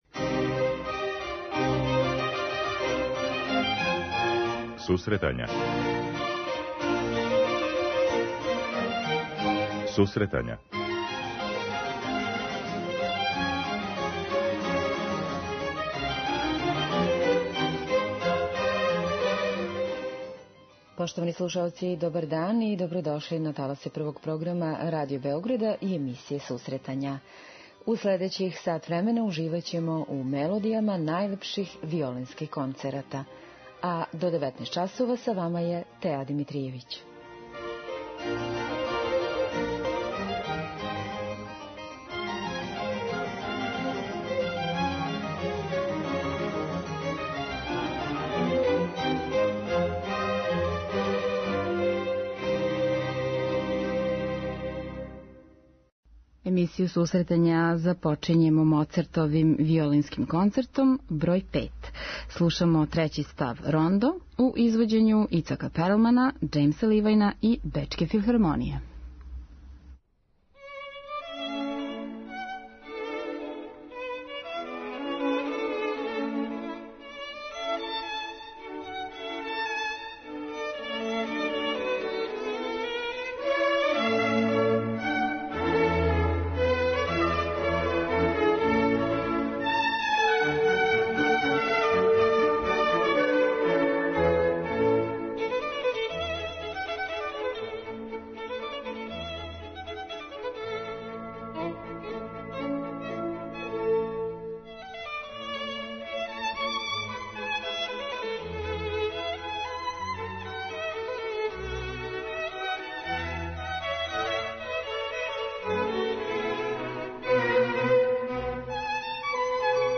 Најлепши виолински концерти